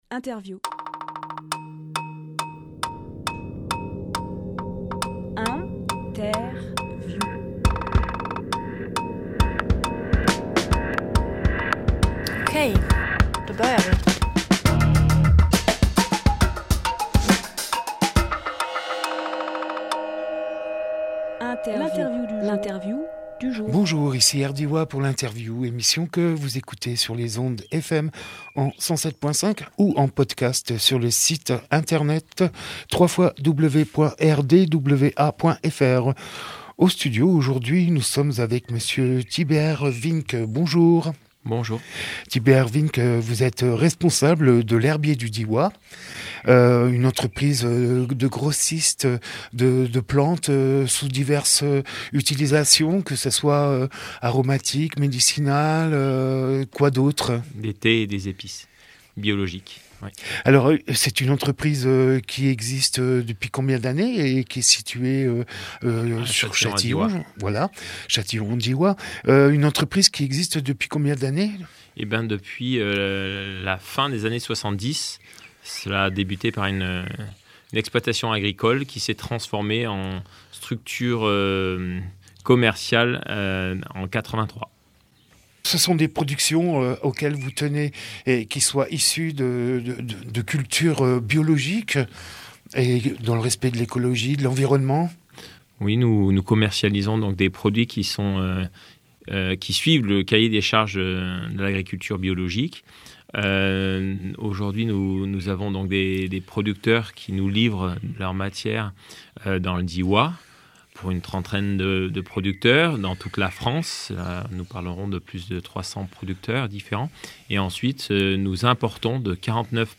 Emission - Interview L’Herbier du Diois Publié le 24 juin 2021 Partager sur…
25.05.21 Lieu : Studio RDWA Durée